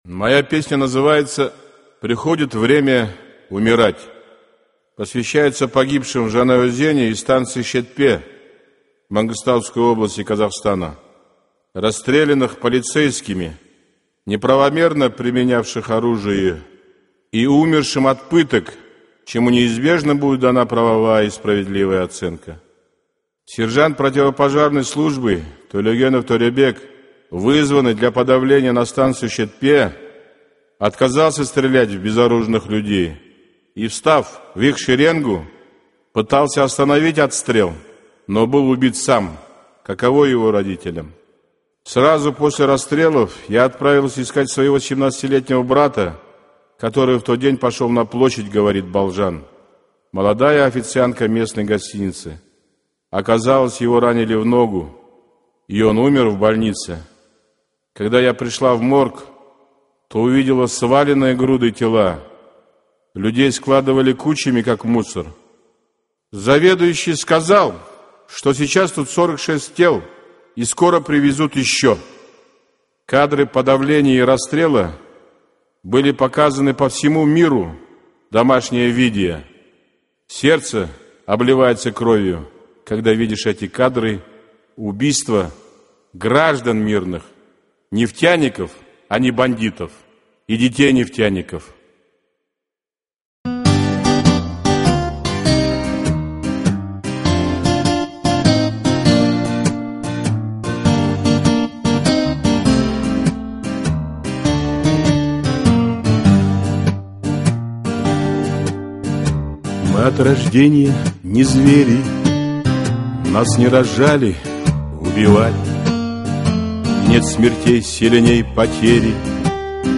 Песня анонимного барда, посвященная событиям В Жанаозене.